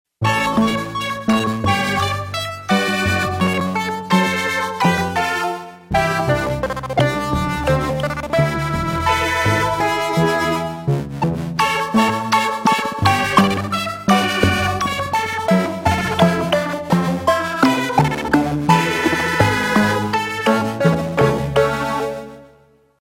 Class: synthesizer module
Synthesis: analog sub
demo factory demo 2